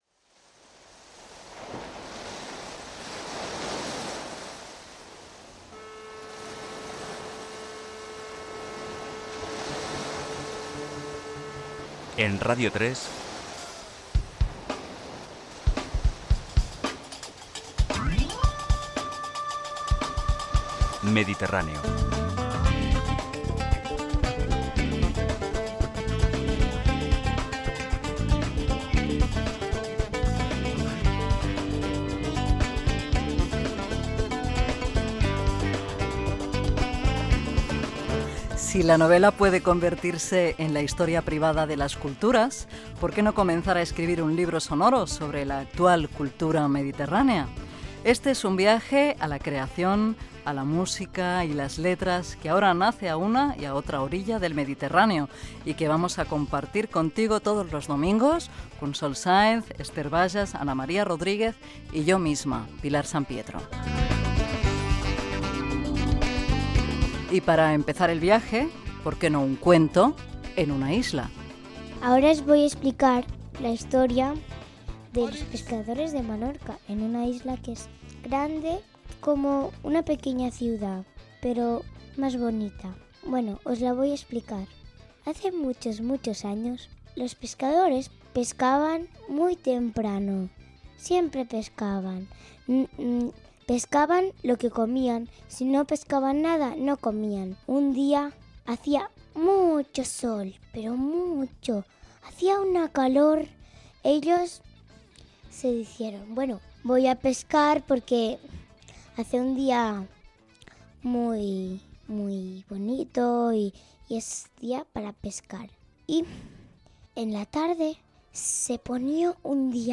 da9bbb3767cd259b7e623a9280ba4201d820b68b.mp3 Títol Radio 3 Emissora Radio 3 Cadena RNE Titularitat Pública estatal Nom programa Mediterráneo Descripció Careta del programa, presentació del primer programa, amb els noms de l'equip. Lectura d'un conte dels pescadors de Menorca.